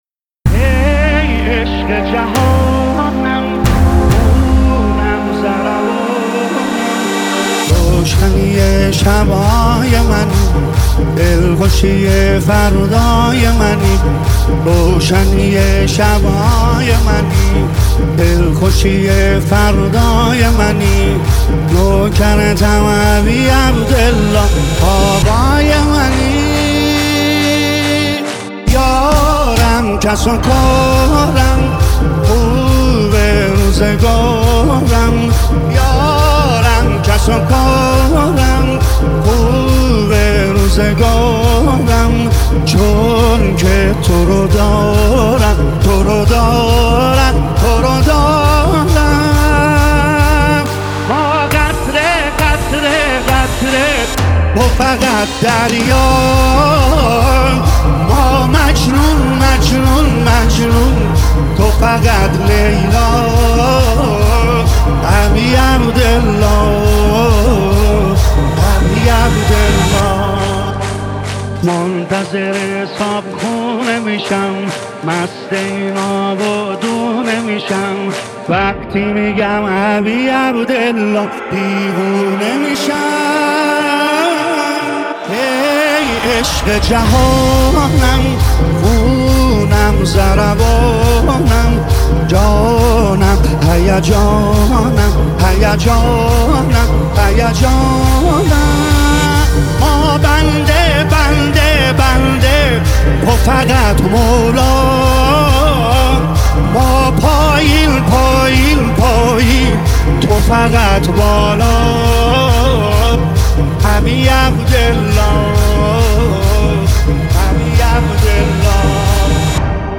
نماهنگ دلنشین
مداحی ماه محرم